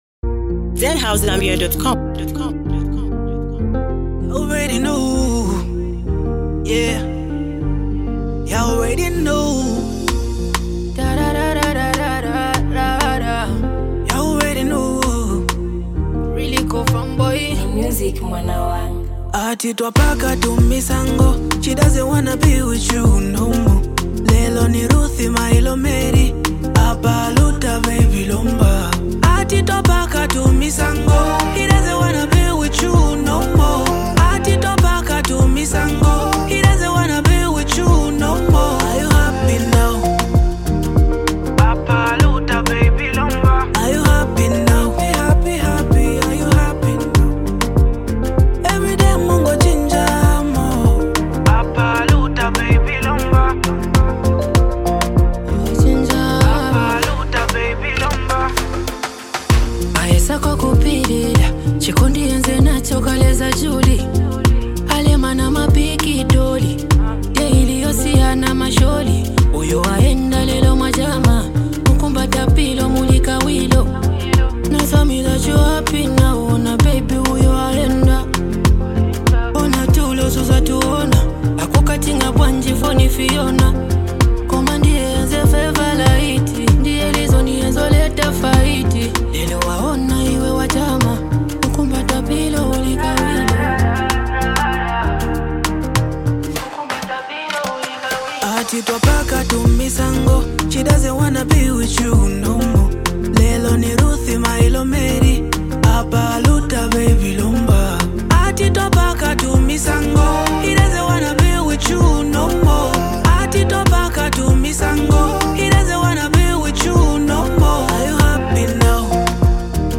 This one’s a certified street anthem — hit repeat!